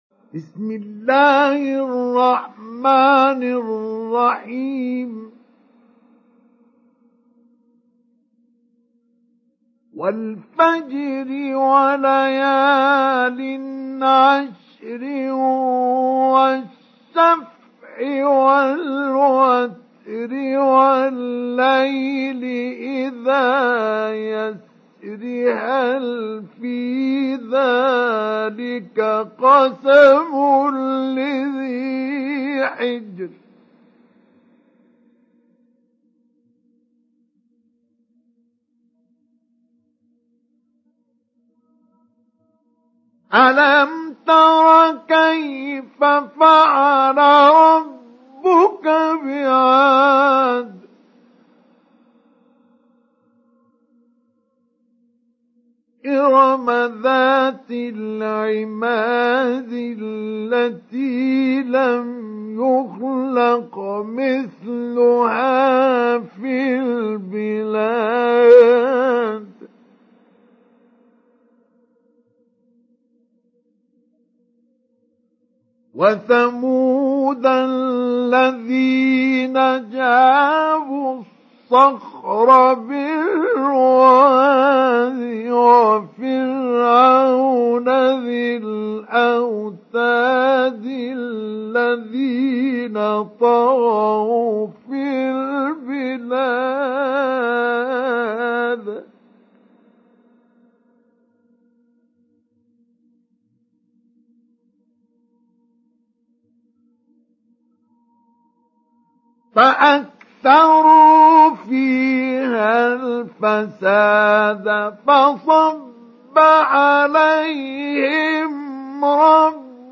Surah Al Fajr mp3 Download Mustafa Ismail Mujawwad (Riwayat Hafs)
Download Surah Al Fajr Mustafa Ismail Mujawwad